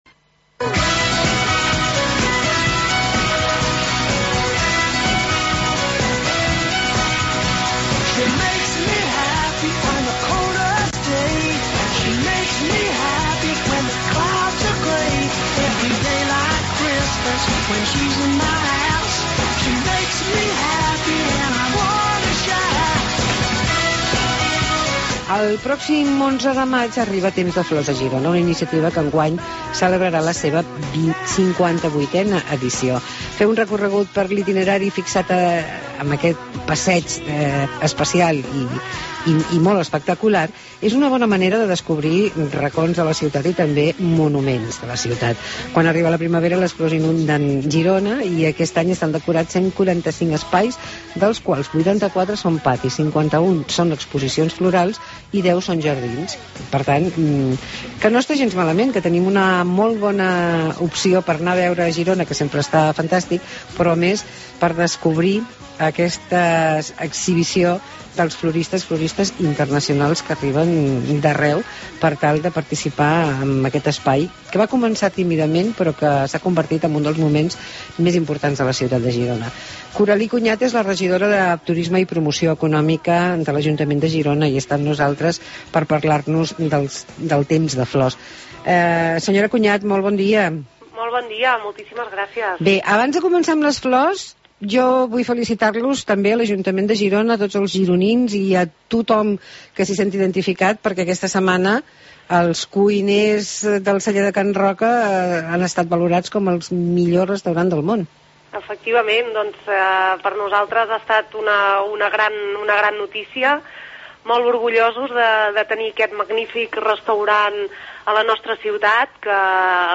Entrevista amb la regidora de l'Ajuntament de Girona, Coralí Conyat, sobre Temps de Flors